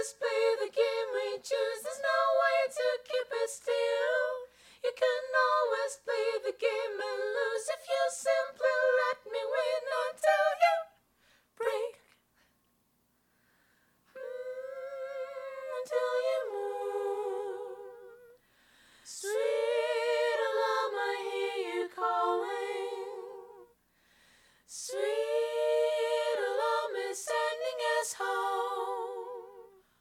"enPreferredTerm" => "Pop"